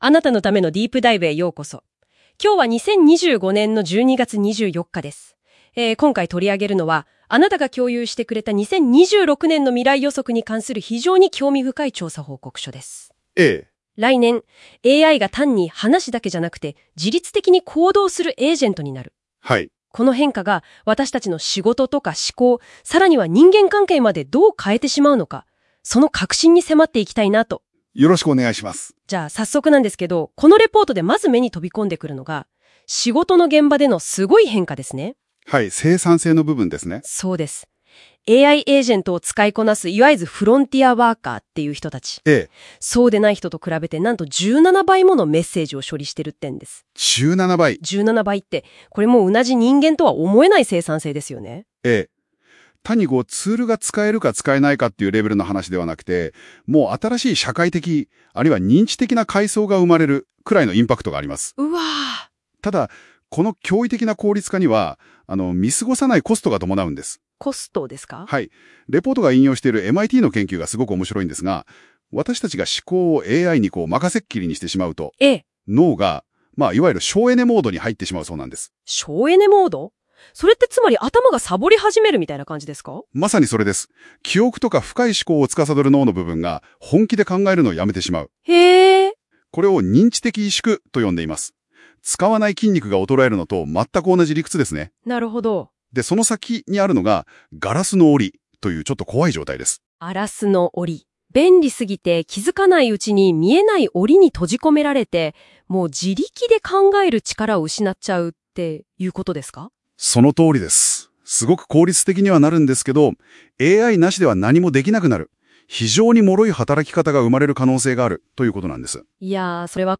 【音声解説】AI支配で失う思考、孤独、そして現実
音声解説を追加しました。